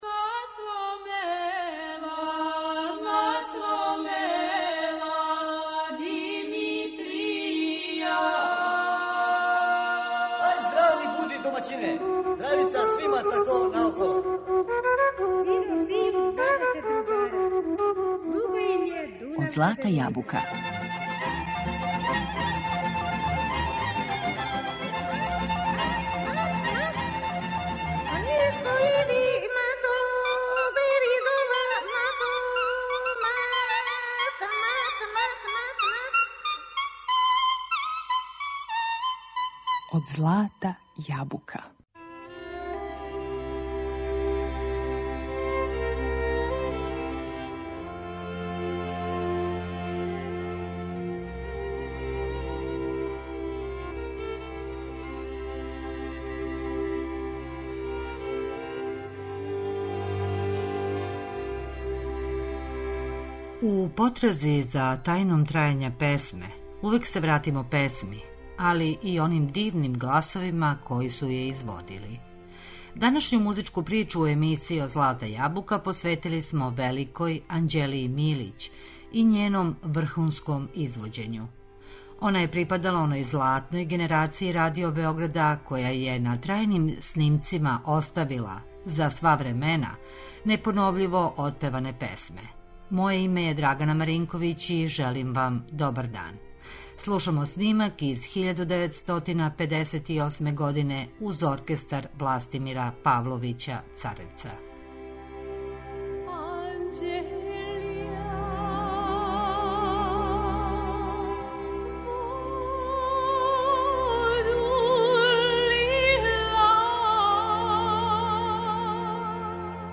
У потрази за тајном трајања песме увек се вратимо песми и оним дивним гласовима који су је изводили.